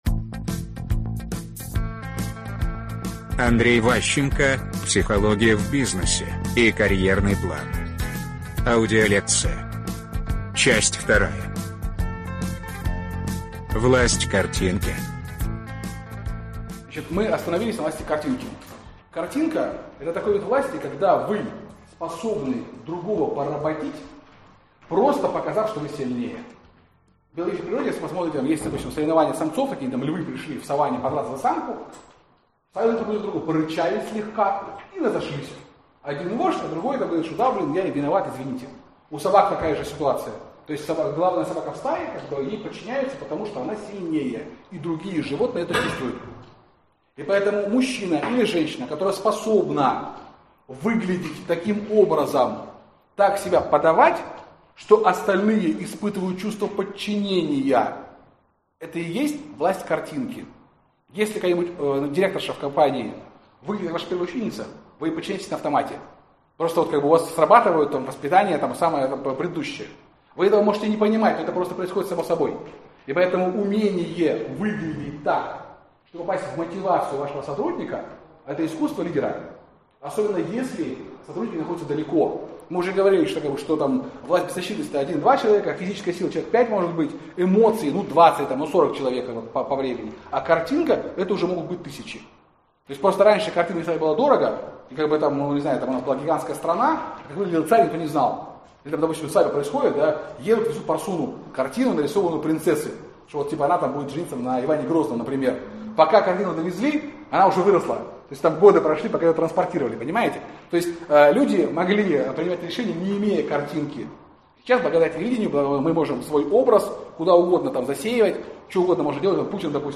Аудиокнига Психология в бизнесе и карьерный план. Лекция 2 | Библиотека аудиокниг